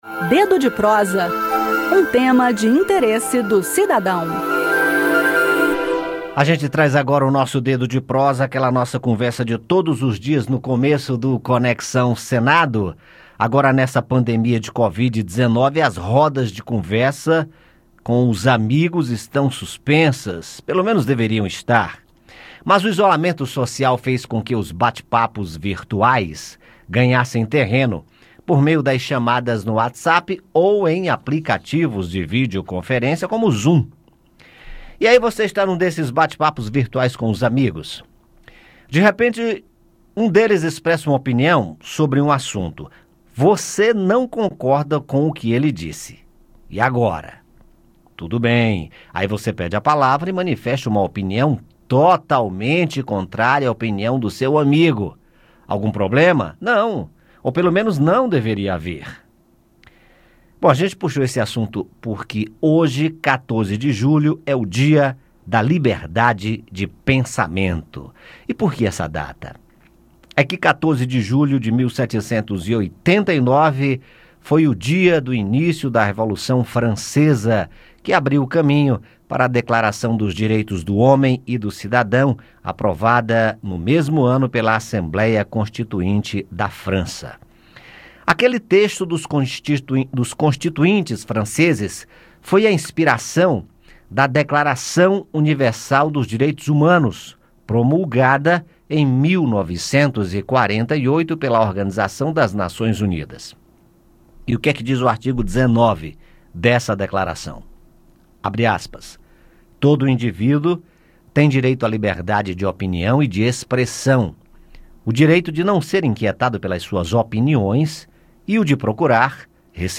O Dedo de Prosa desta terça-feira (14) traz esse tema no Dia da Liberdade de Pensamento, comemorado em homenagem ao dia do início da Revolução Francesa (14/07/1789). Ouça o bate-papo